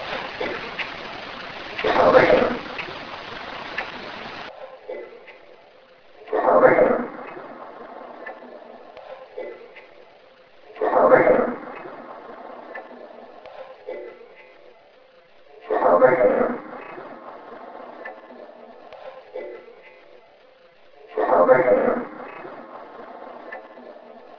Get Out of Here - A very clear and chilling statement recorded in the middle of the night in Room 36.
The clip has the original sound, a cleaned version (x2) and a slowed version (x2).